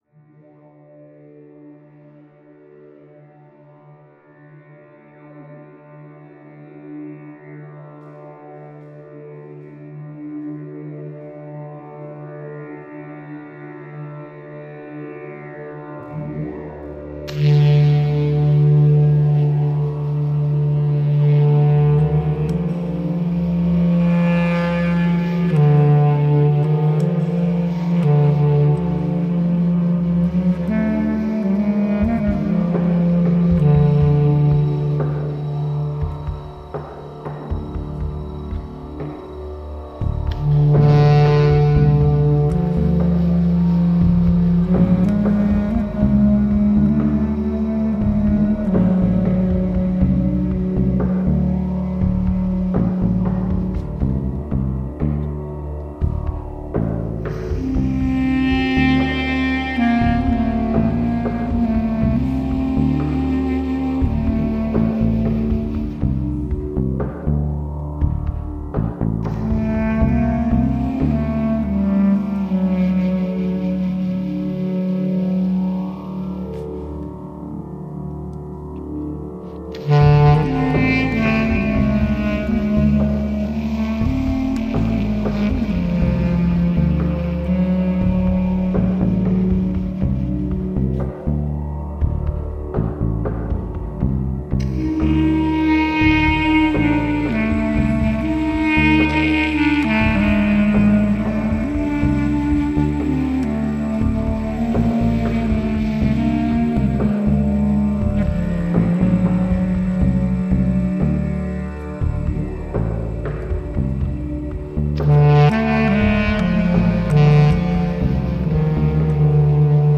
Ein herbstlicher Spaziergang im Nebel, mit der Klarinette